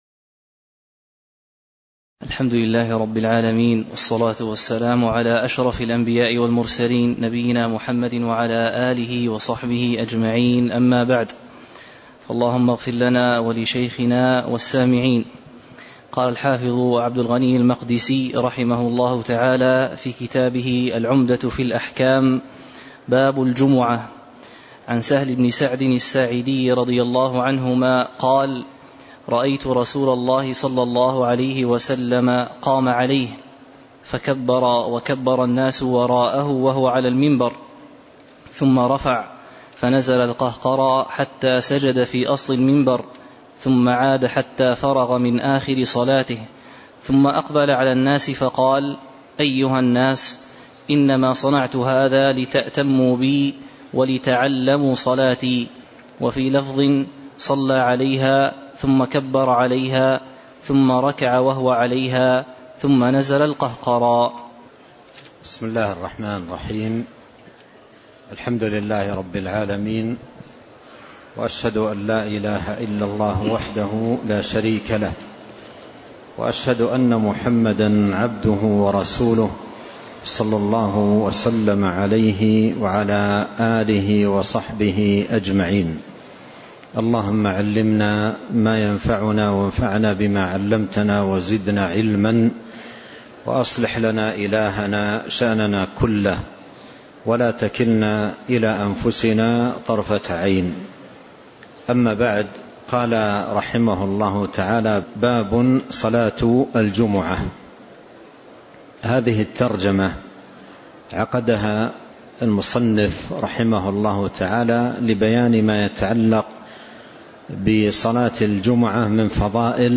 تاريخ النشر ١٧ جمادى الأولى ١٤٤٤ هـ المكان: المسجد النبوي الشيخ